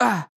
damage4.wav